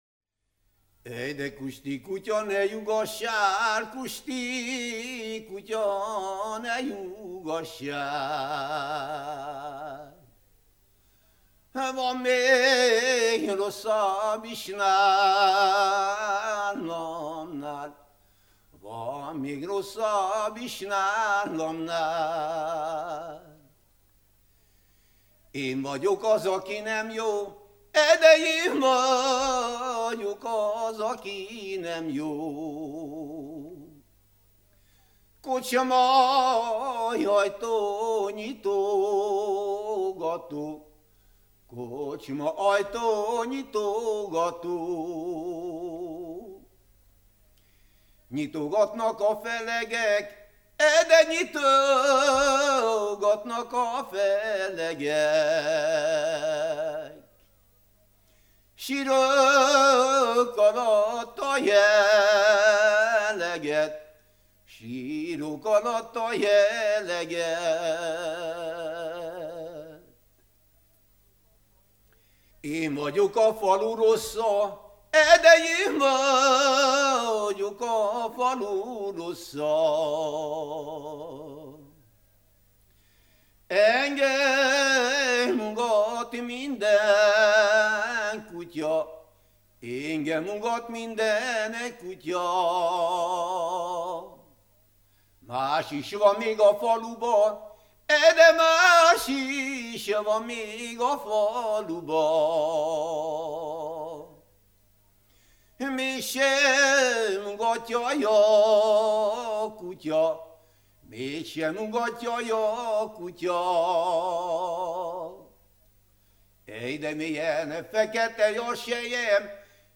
ének
Türe
Nádas mente (Kalotaszeg, Erdély)